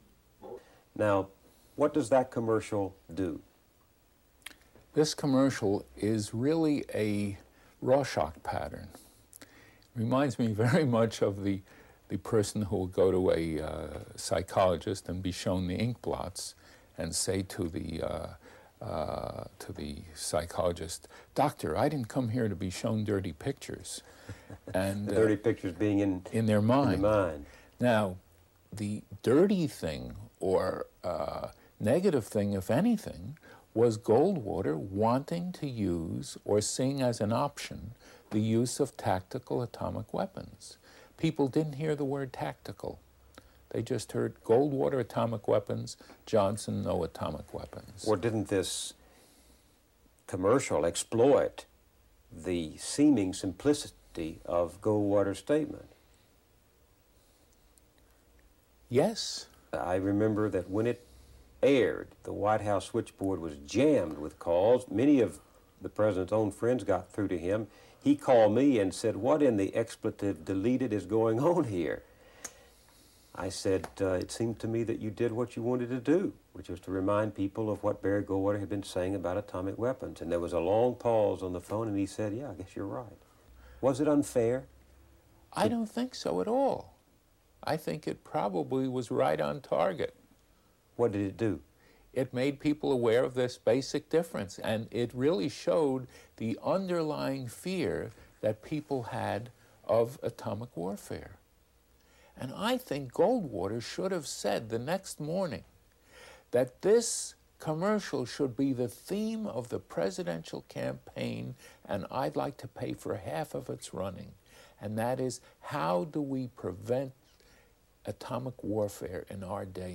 Here's Bill Moyers interviewing Schwartz on the ad much later in 1982: